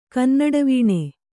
♪ kannaḍavīṇe